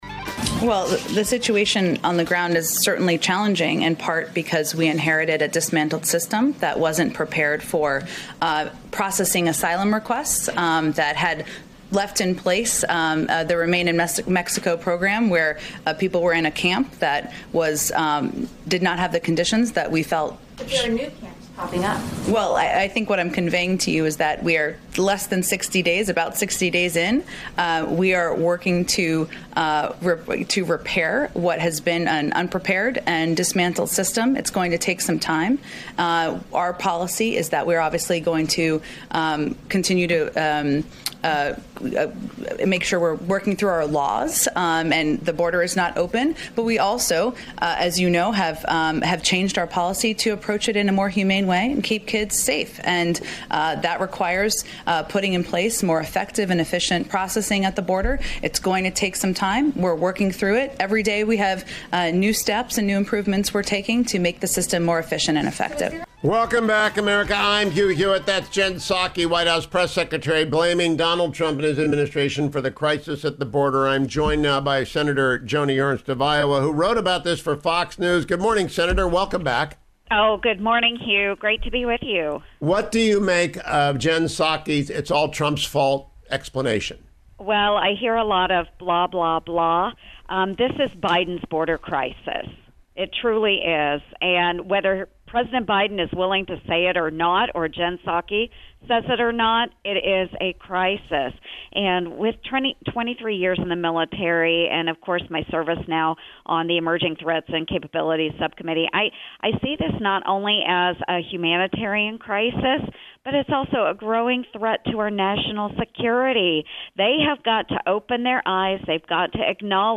Senator Joni Ernst joined me this AM, and she is leading the charge to reject Biden nominee Colin Kahl: